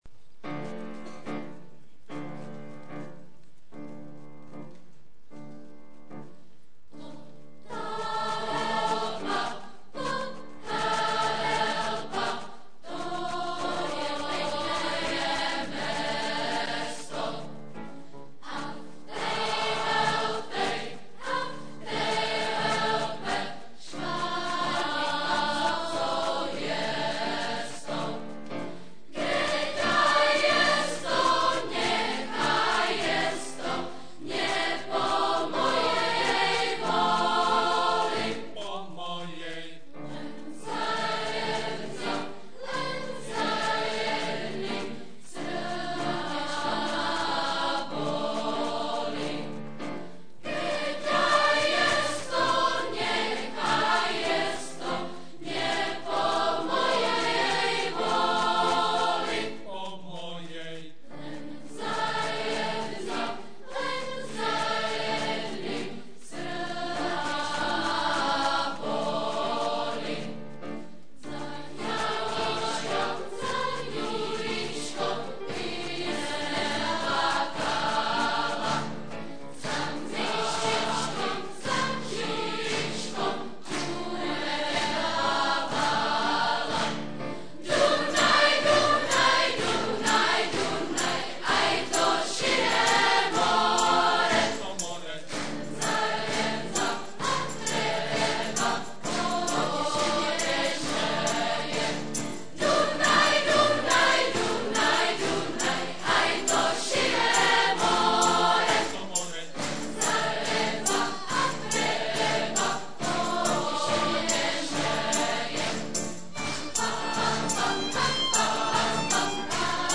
* REPERTOÁR,mp3,videa * :: OTAKAR - smíšený pěvecký sbor VYSOKÉ MÝTO
Lidové písně různých národů